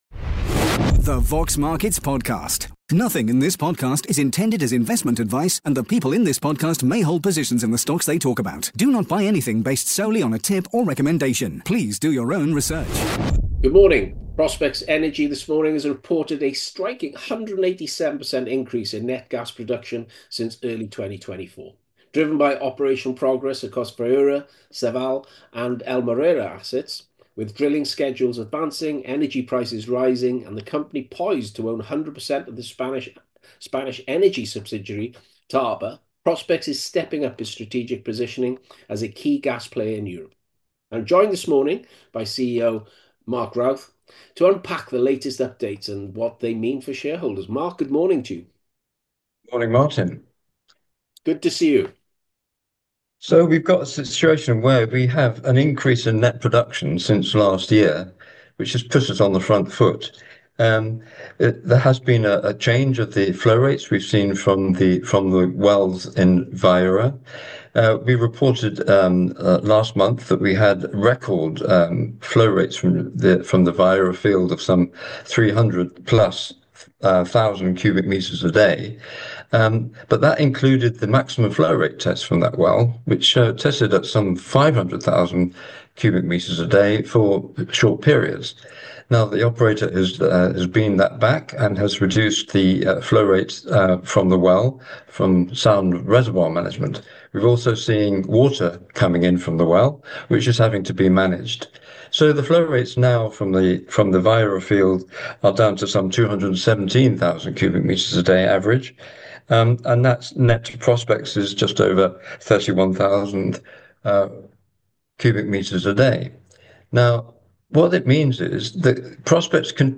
The Vox Markets Podcast / Q&A